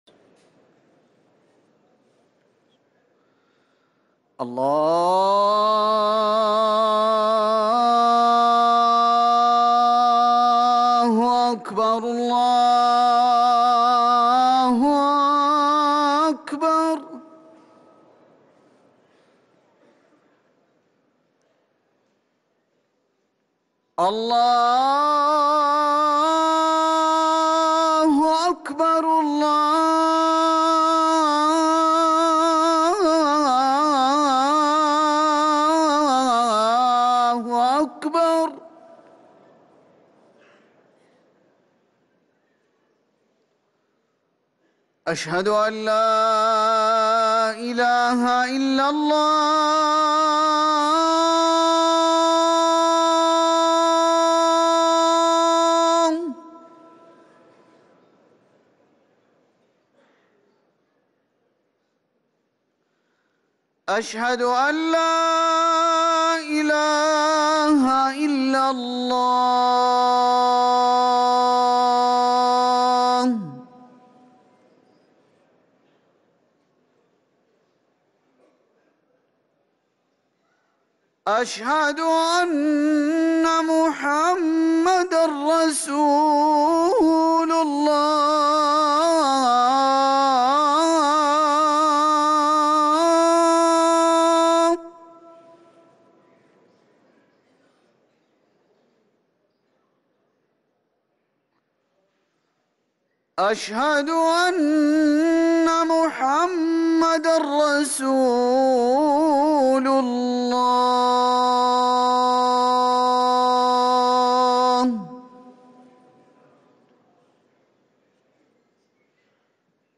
أذان العصر
ركن الأذان